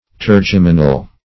Search Result for " tergeminal" : The Collaborative International Dictionary of English v.0.48: Tergeminal \Ter*gem"i*nal\, Tergeminate \Ter*gem"i*nate\, a. [See Tergeminous .]